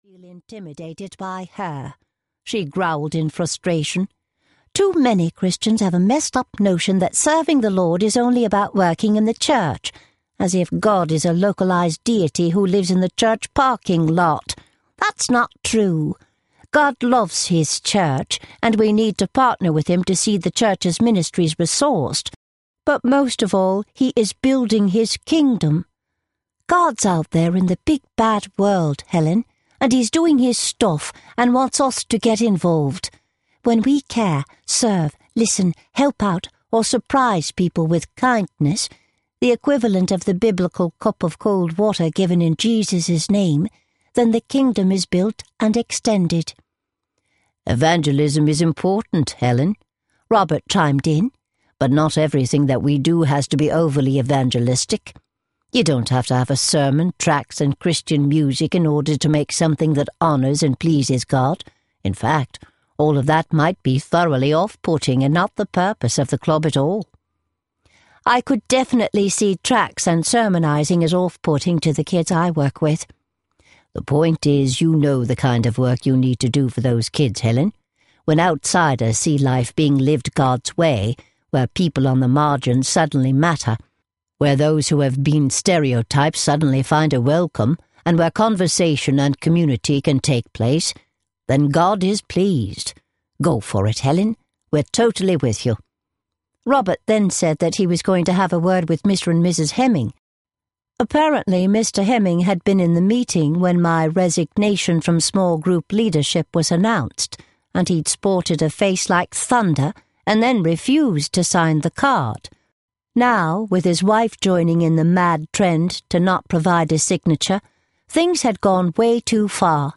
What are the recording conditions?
– Unabridged